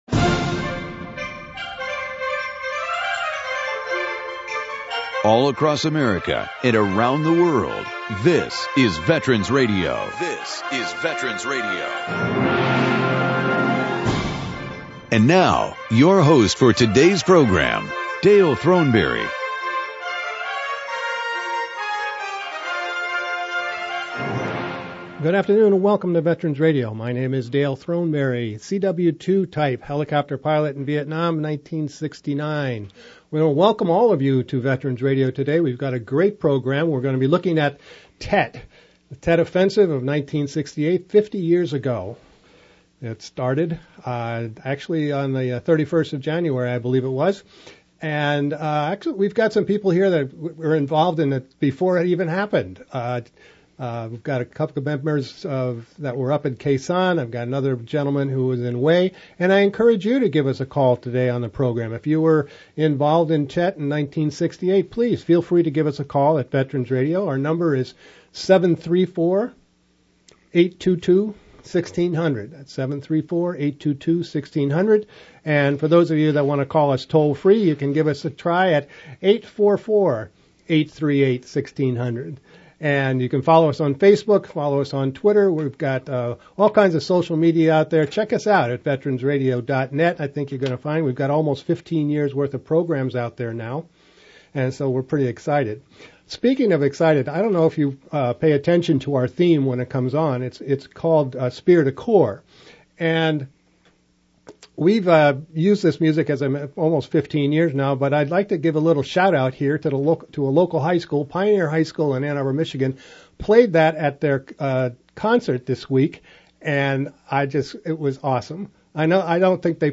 "Tet" 1968 - First Hand Recollections from USMC, Army, and Navy Seabee Veterans